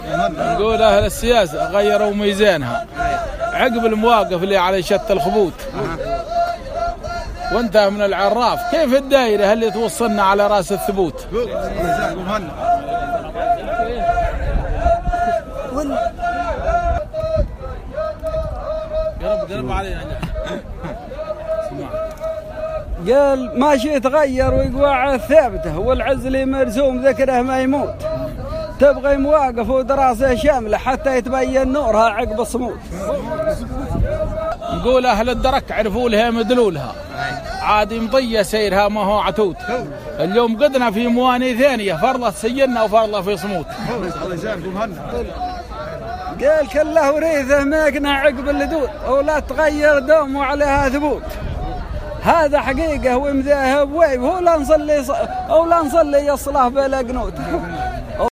محاورة